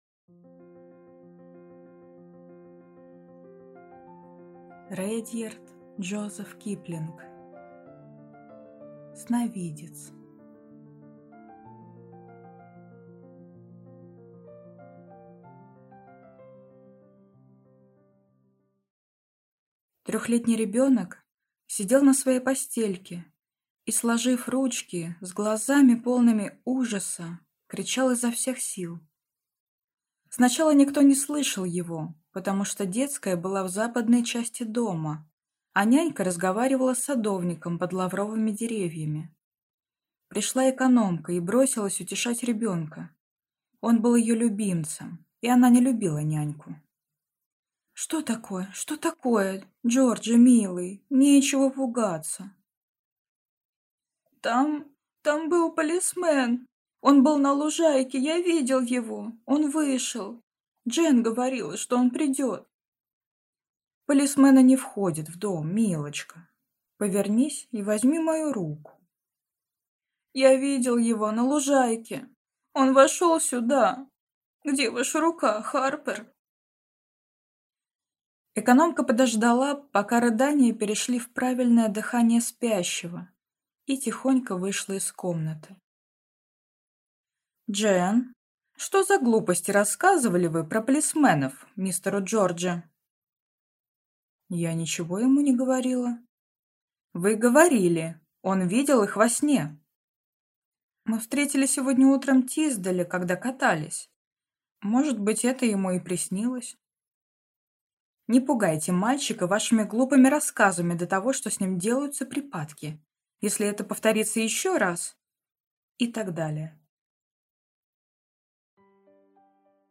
Аудиокнига Сновидец | Библиотека аудиокниг